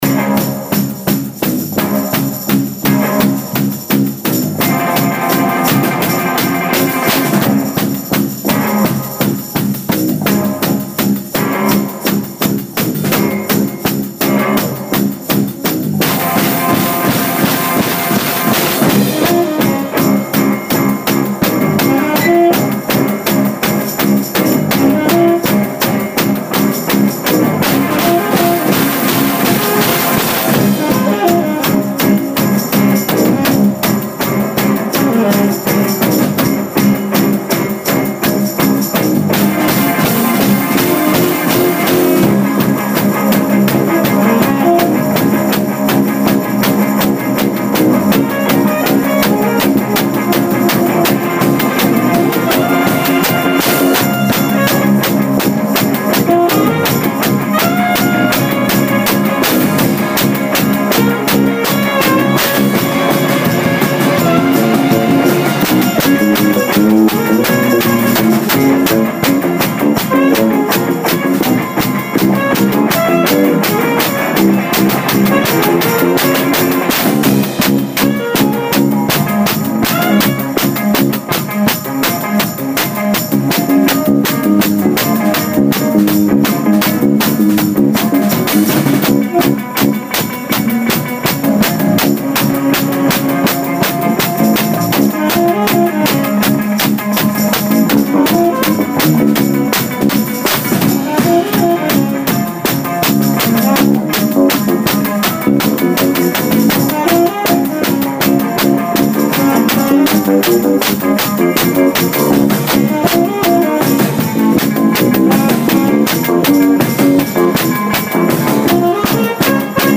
bass
guitar
sax
tamborine
drums